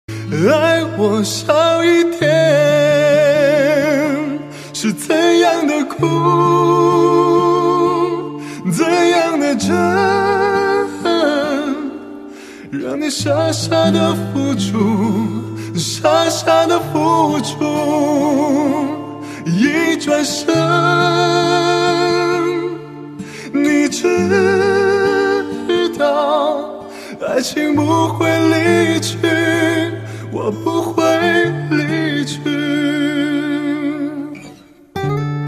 M4R铃声, MP3铃声, 华语歌曲 79 首发日期：2018-05-15 14:15 星期二